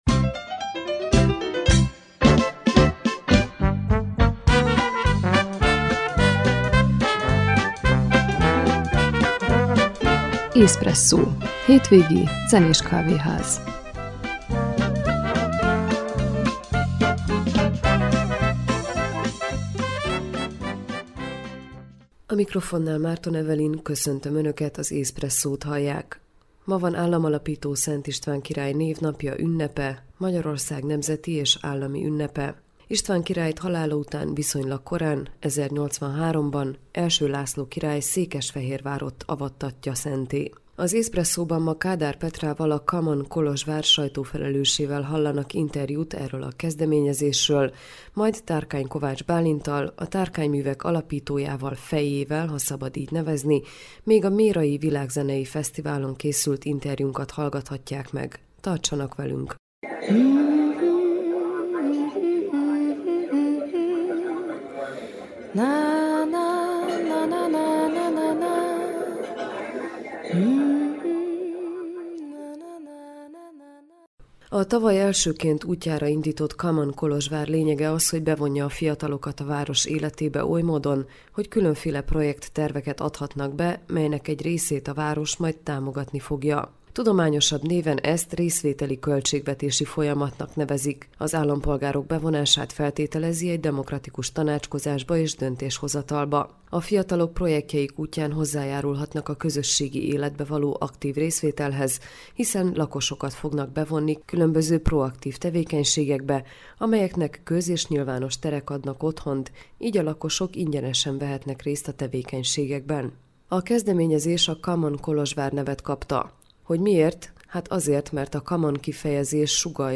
még a mérai világzenei fesztiválon készült interjút hallgathatják meg